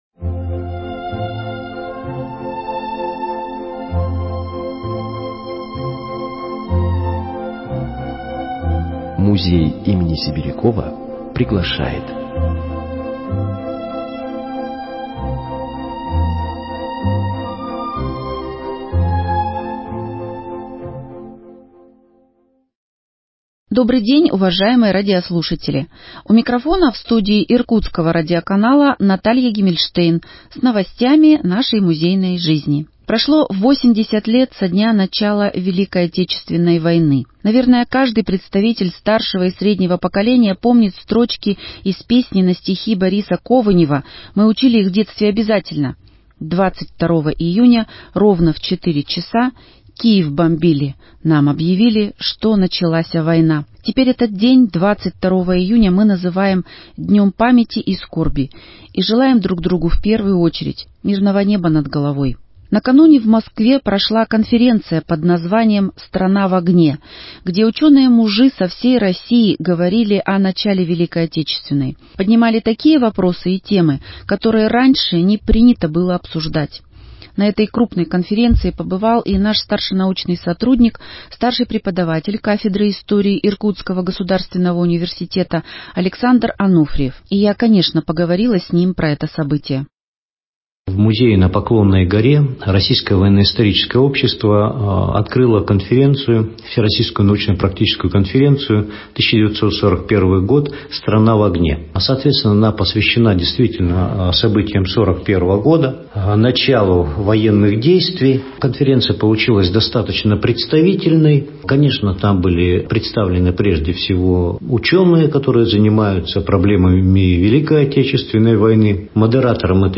Еженедельная передача, выходящая по пятницам.